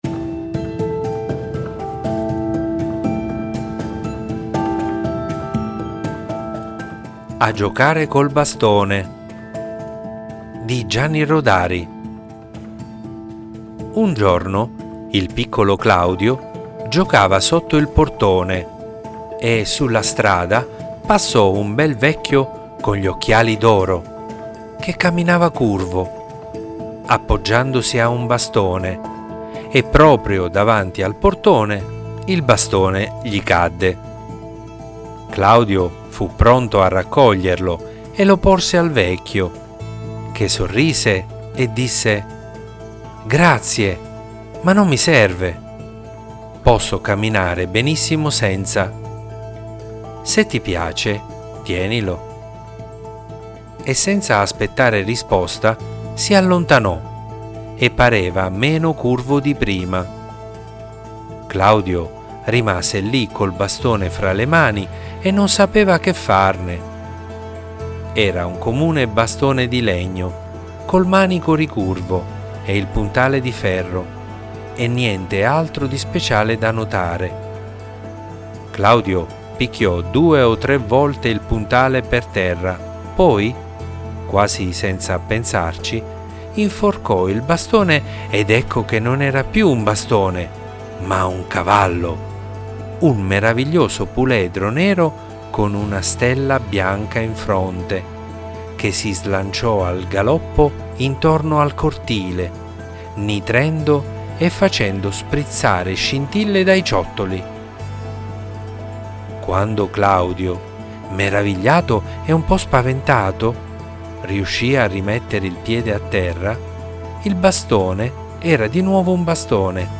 Leggi e ascolta "A giocare col bastone" di Gianni Rodari
Ciao a tutti! questa sera vi leggo una piccola perla del grande Gianni Rodari tratta dalla raccolta favole al telefono.